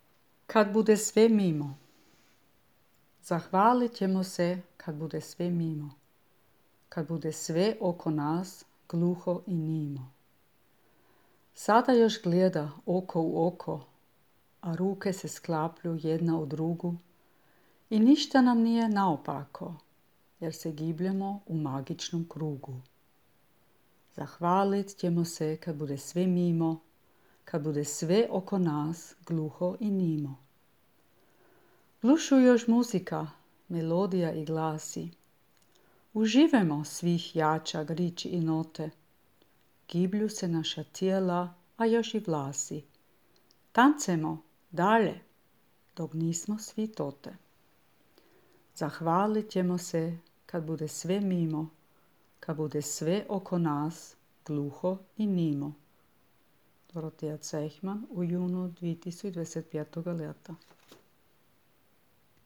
čita